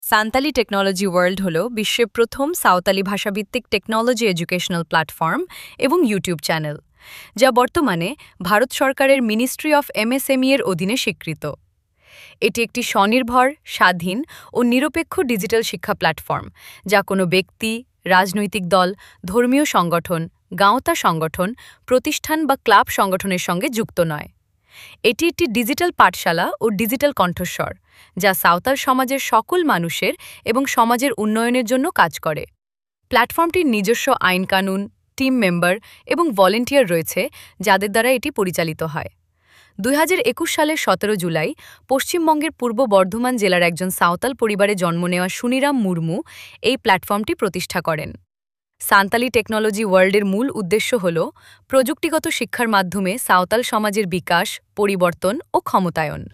ElevenLabs_Text_to_Speech_audio.mp3